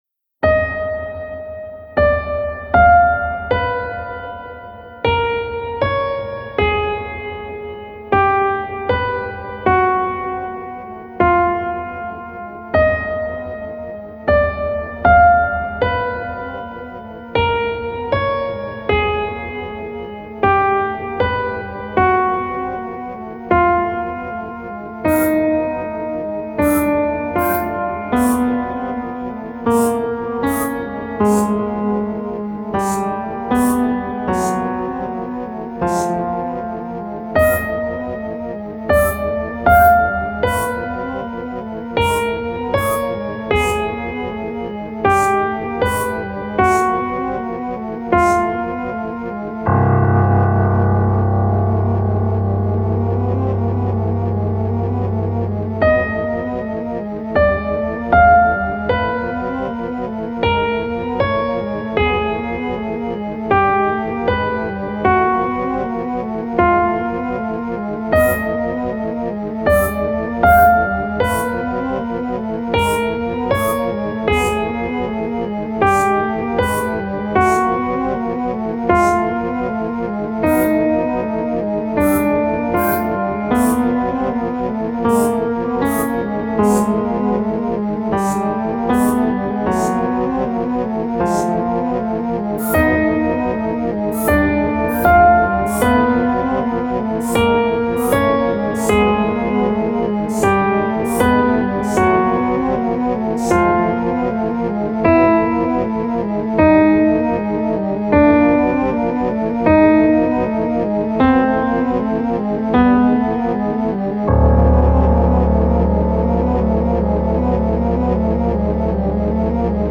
【用途/イメージ】　怪談　都市伝説　ダーク　不気味　不安　緊張
ピアノ　ノイズ